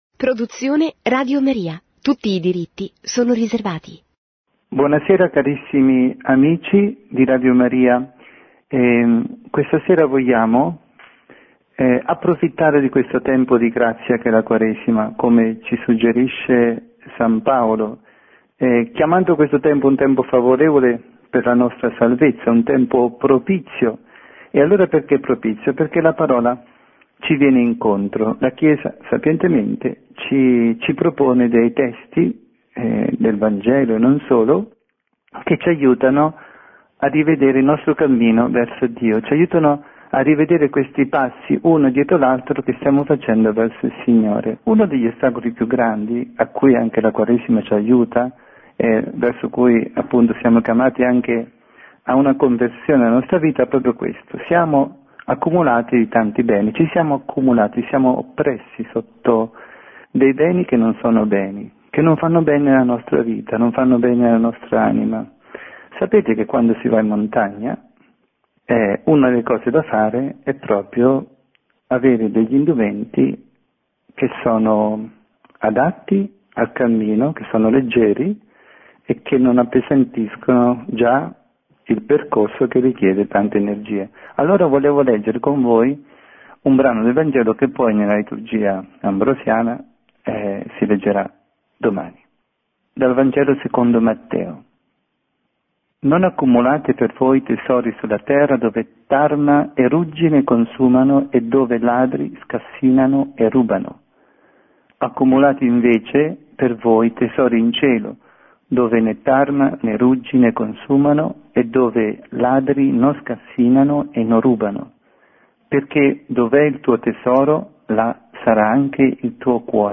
Catechesi
trasmessa in diretta su RadioMaria